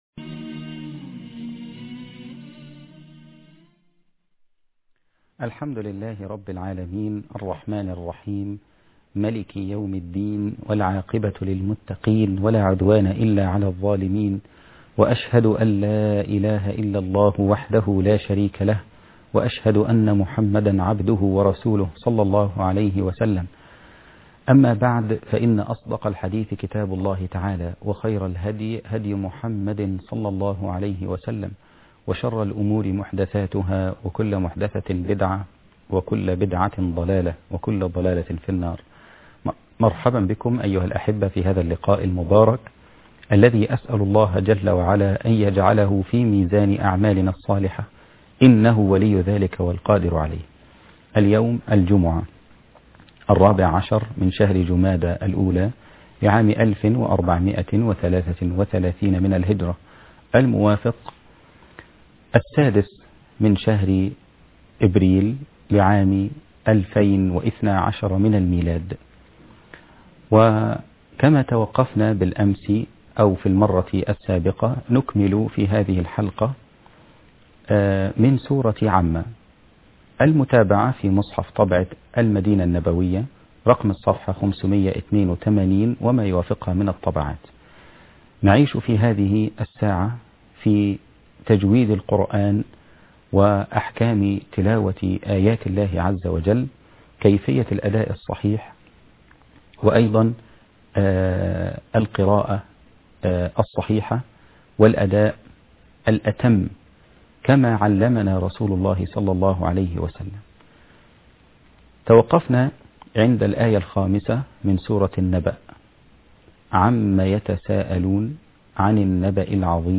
المقراة